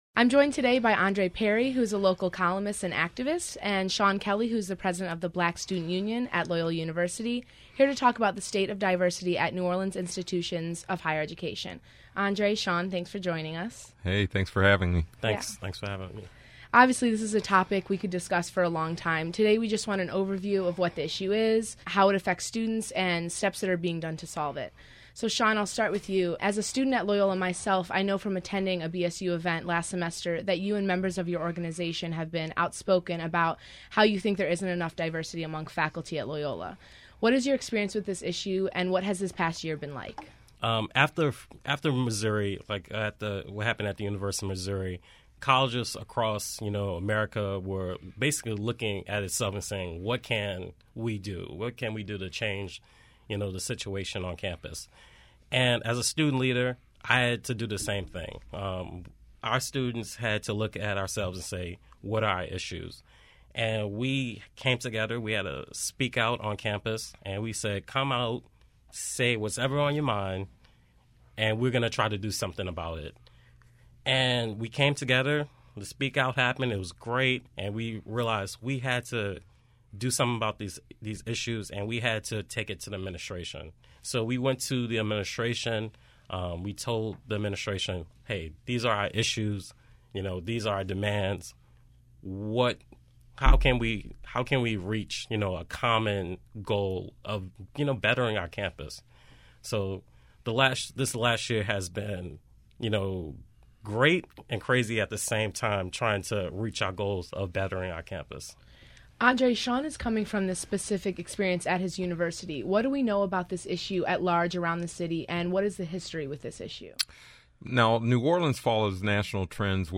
Diversity among faculty interview